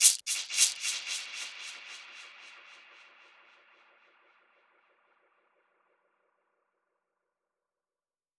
Index of /musicradar/dub-percussion-samples/85bpm
DPFX_PercHit_C_85-06.wav